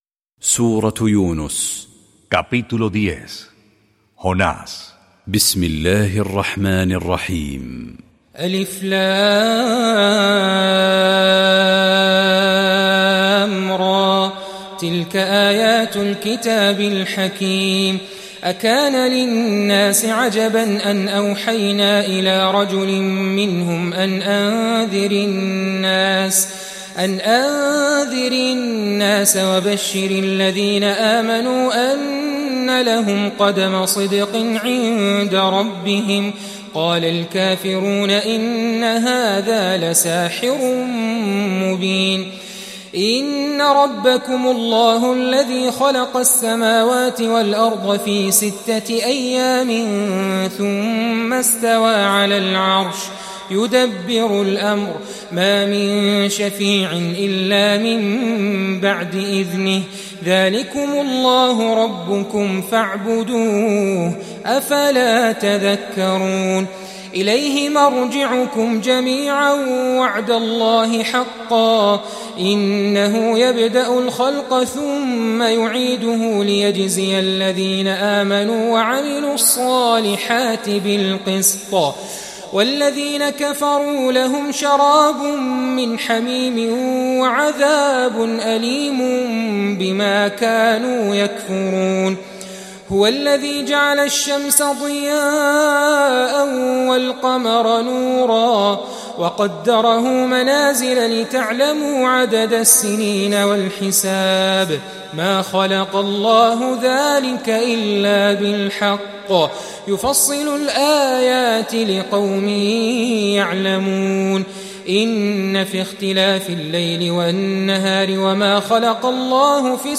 EL SAGRADO CORÁN Recitado